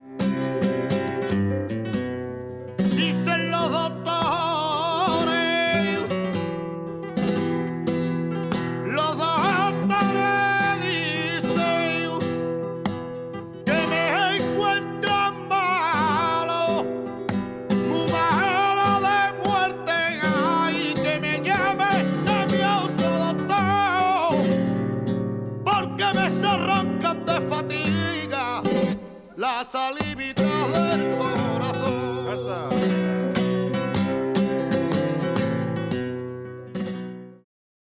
Tientos (otros)